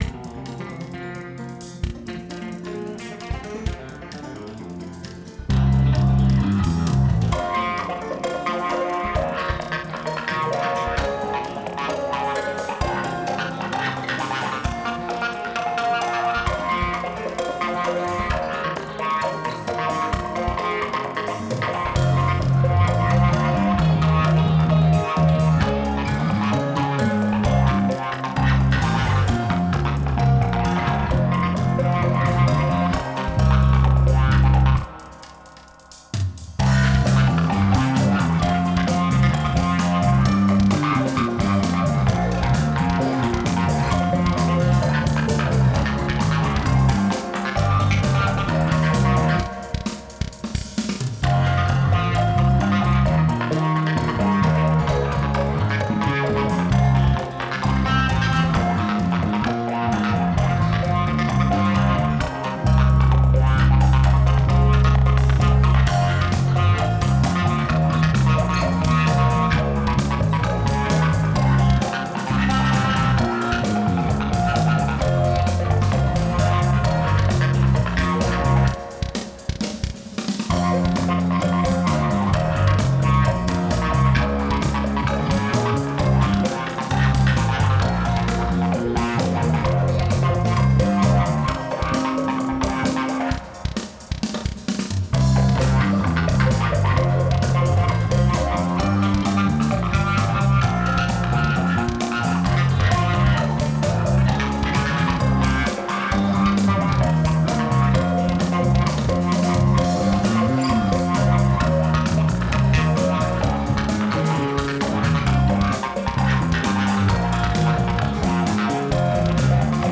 Genre: Hip-Hop